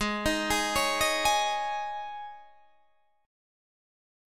G#sus4 chord